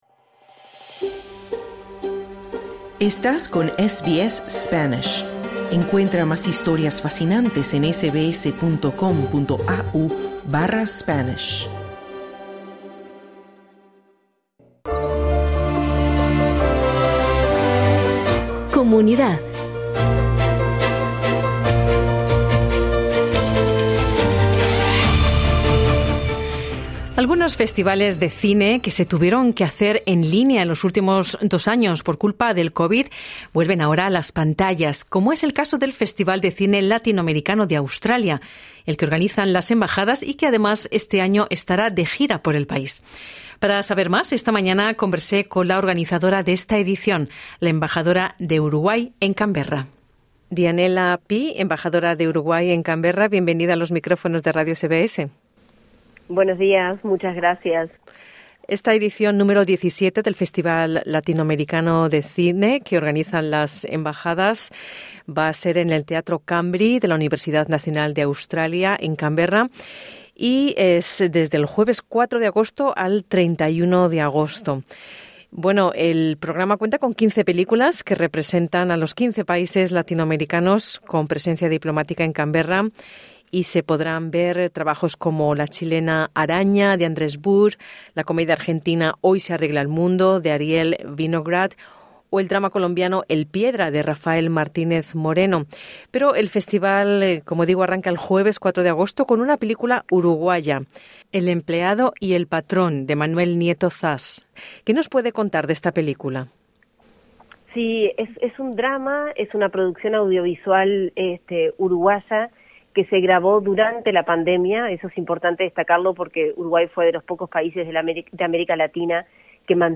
Escucha la entrevista completa con la embajadpora de Uruguay en Canberra, Dianela Pi.